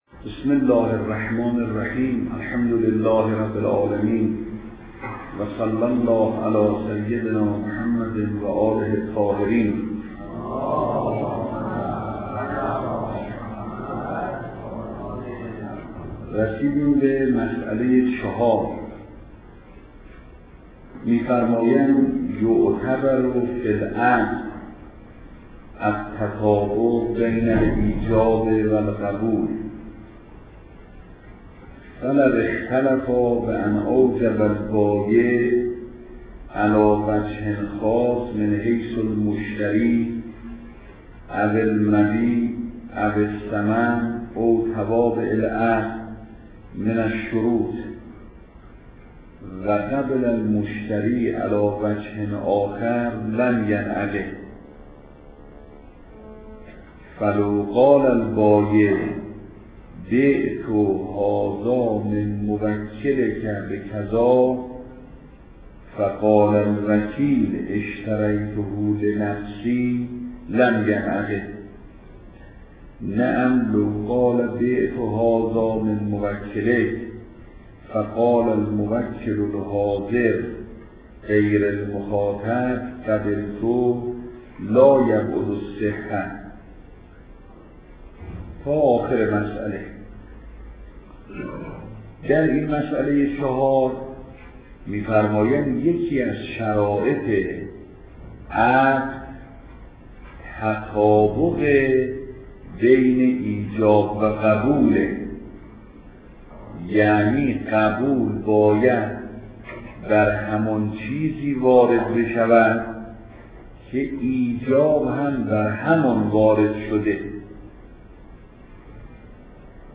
درس